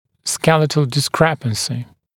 [‘skelɪtl dɪs’krepənsɪ][‘скелитл дис’крэпэнси]скелетные несоответствия, нарушении соотношения челюстей